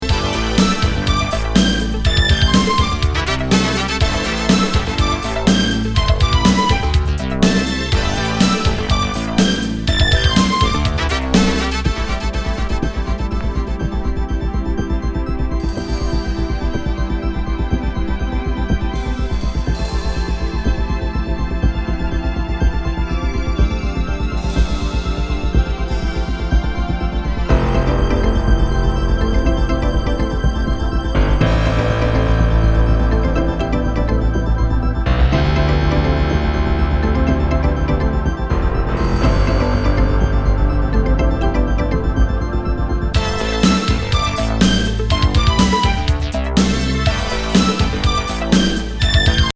シンセ・コズミック80’Sモダン・ブギー!